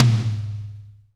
-DRY TOM 2-L.wav